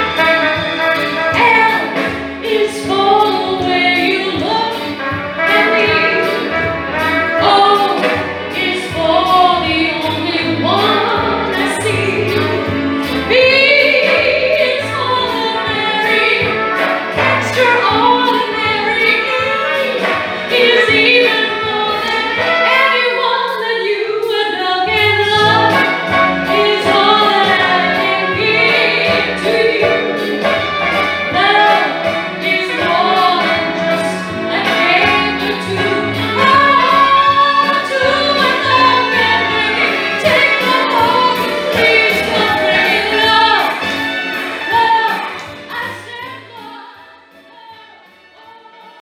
(from live recording)
Elegant, expressive and versatile performer